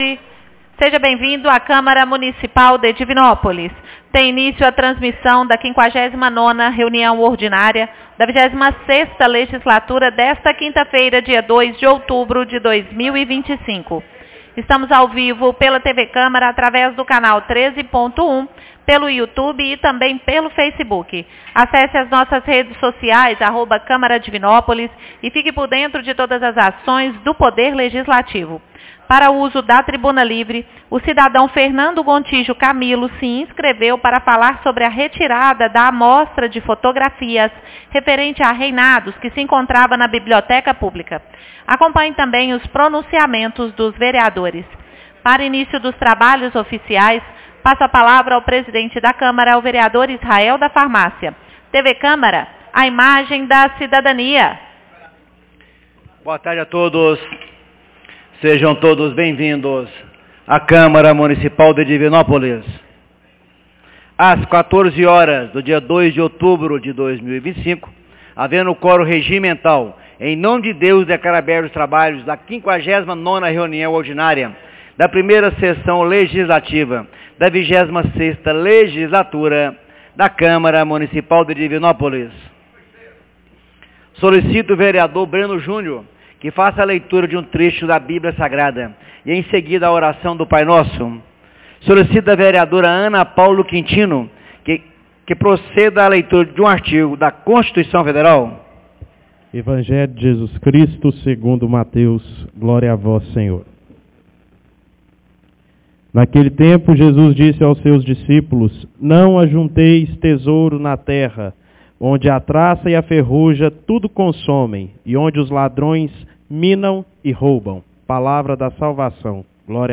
59ª Reunião Ordinaria 02 de Outubro de 2025